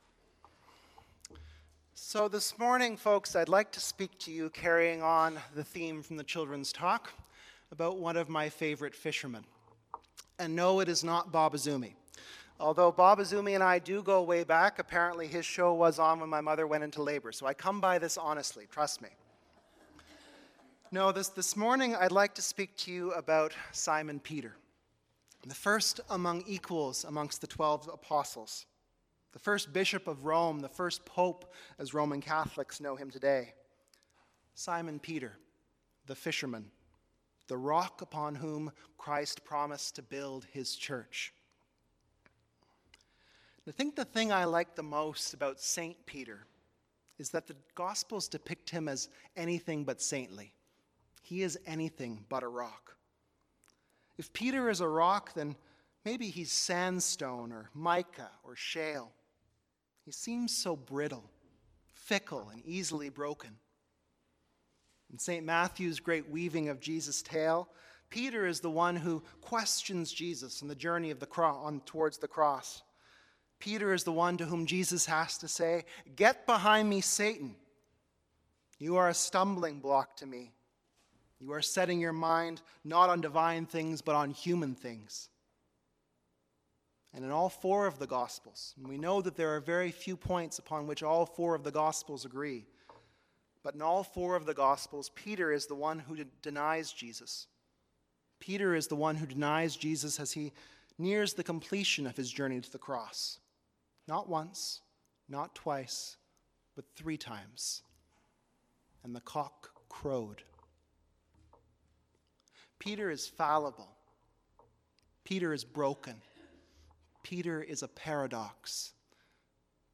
Sermons | Parish of the Valley